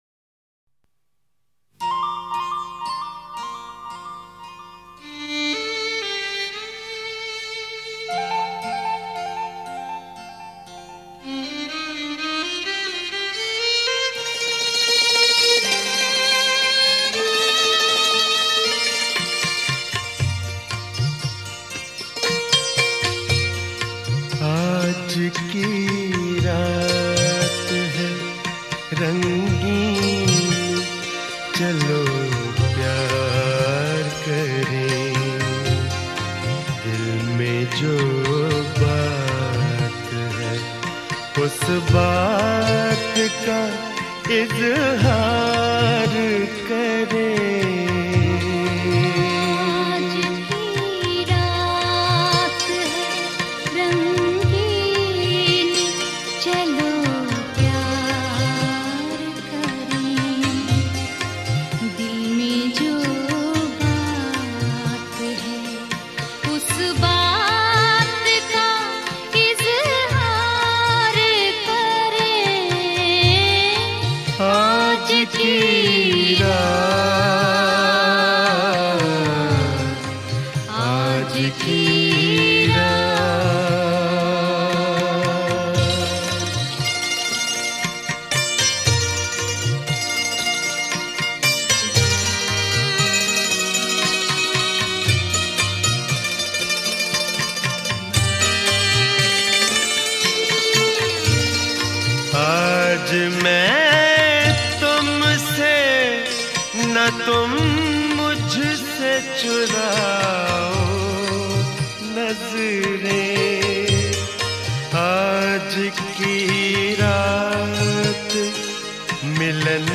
Ghazal